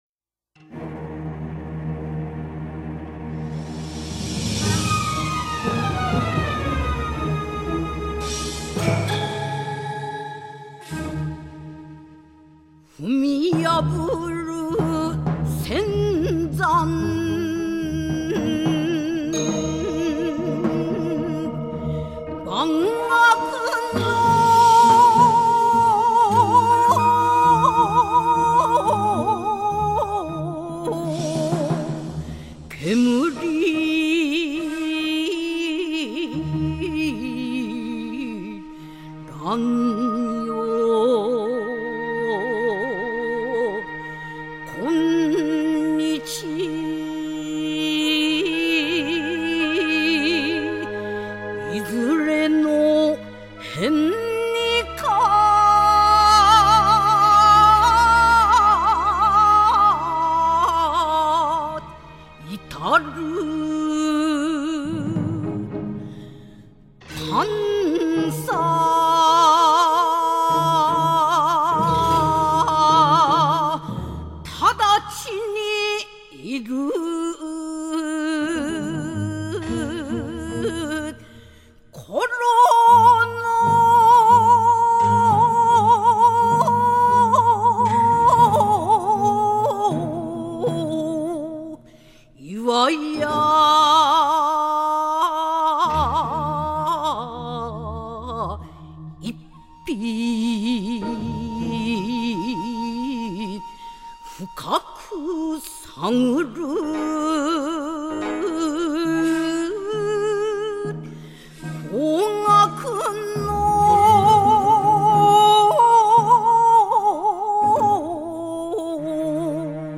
吟者：CD①収録